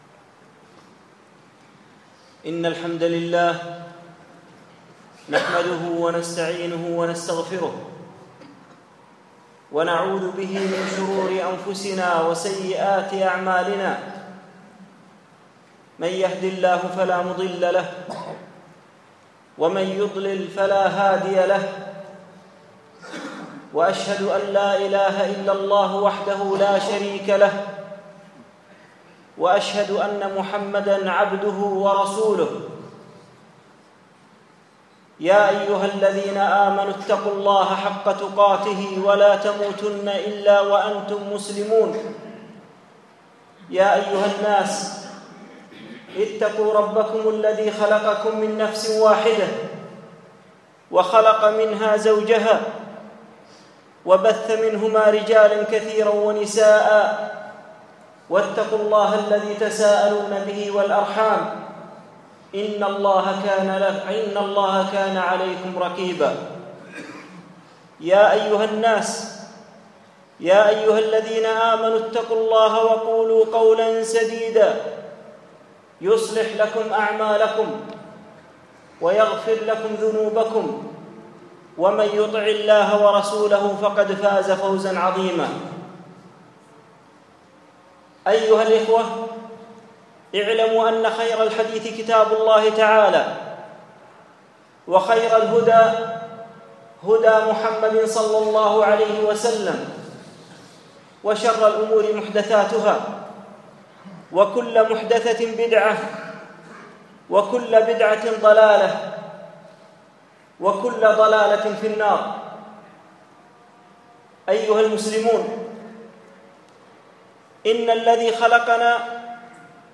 أهمية العقيدة - خطبة